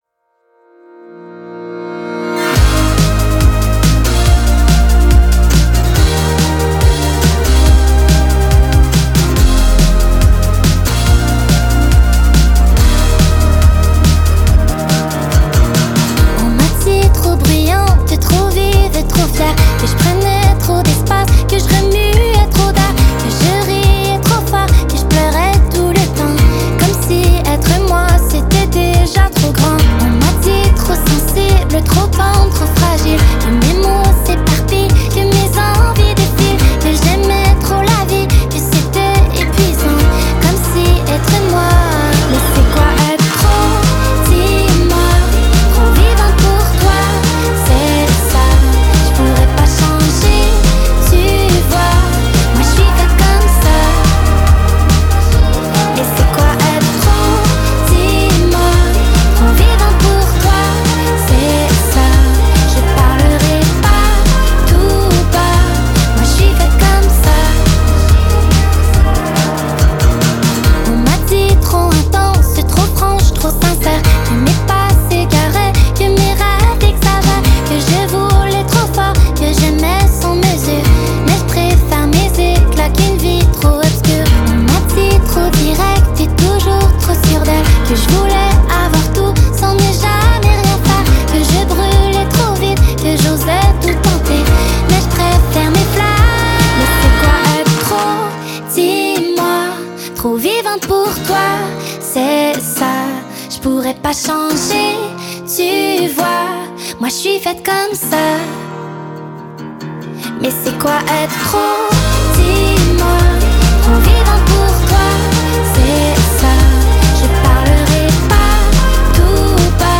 jeune auteure-compositrice-interprète
sa voix douce